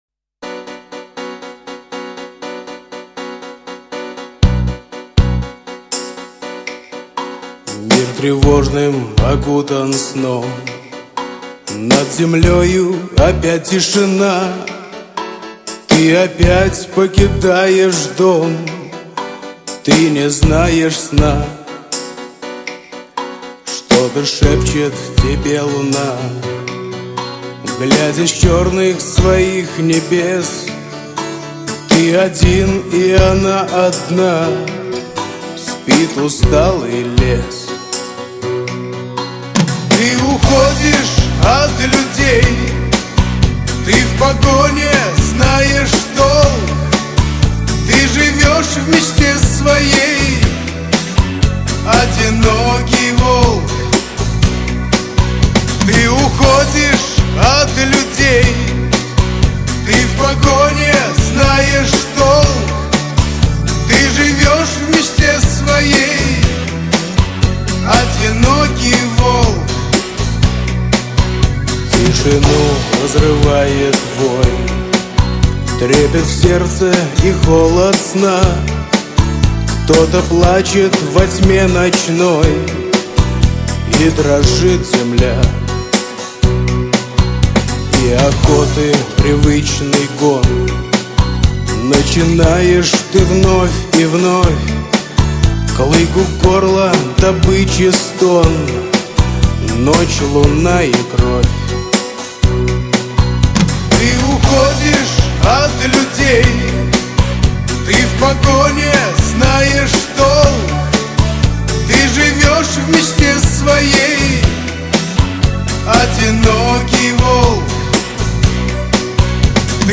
Категория: Шансон песни